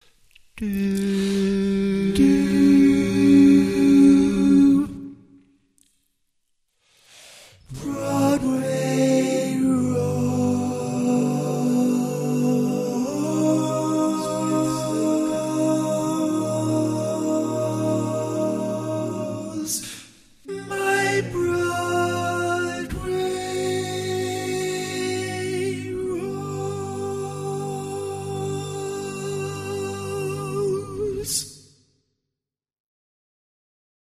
Key written in: G Major
How many parts: 4
Type: Barbershop
Learning tracks sung by